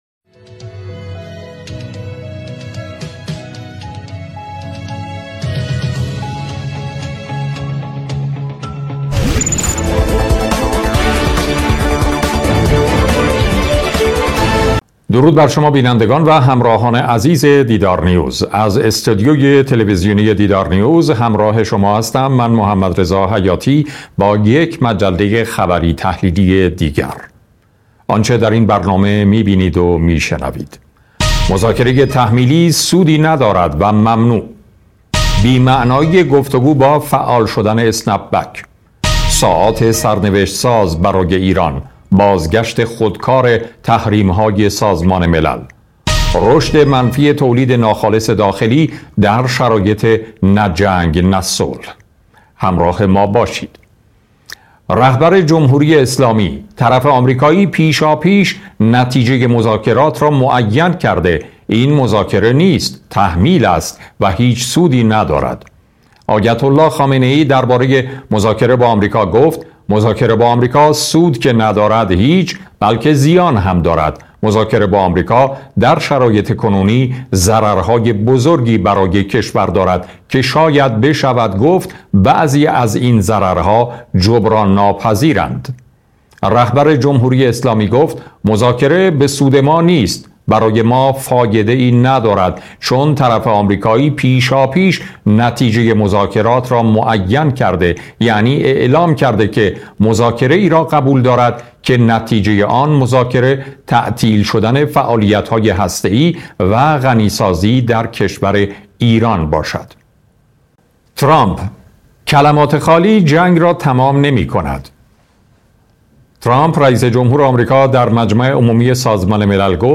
صدای مجله خبری تحلیلی دیدارنیوز با اجرای محمدرضا حیاتی و با حضور کارشناسان و صاحب نظران را می‌توانید اینجا گوش دهید.